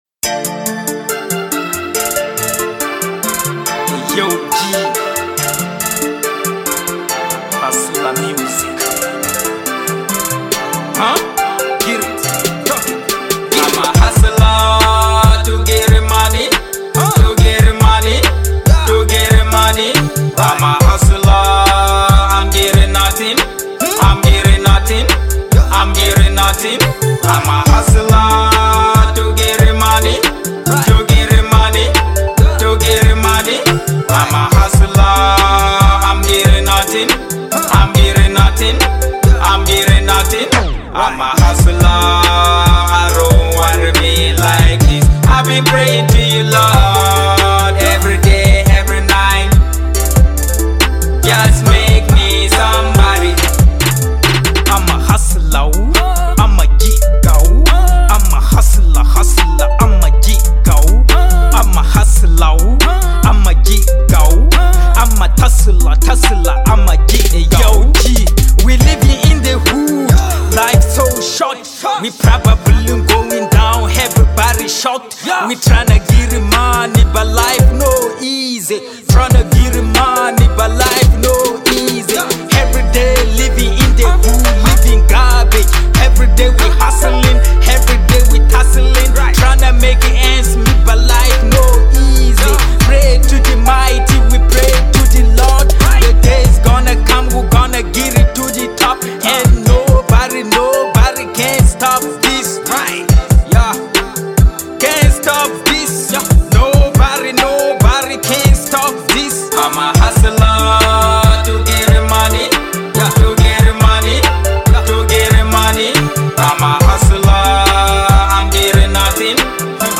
EstiloWorld Music